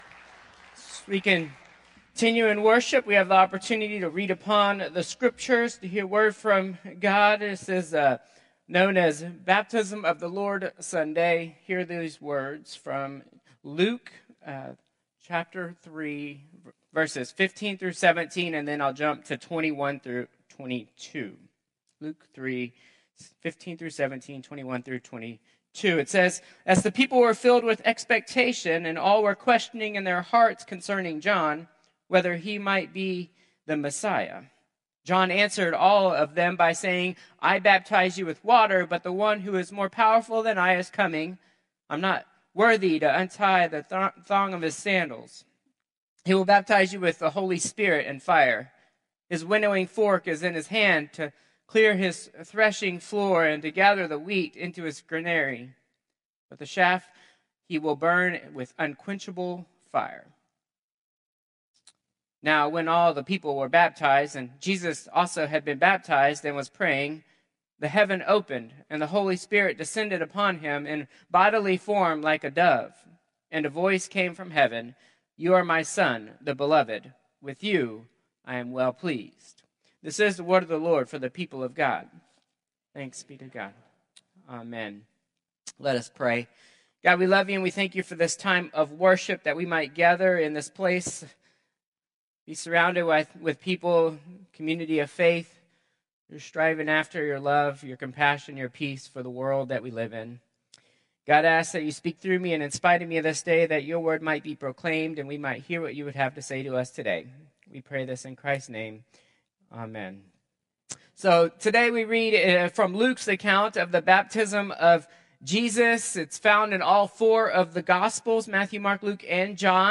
Contemporary Service 1/12/2025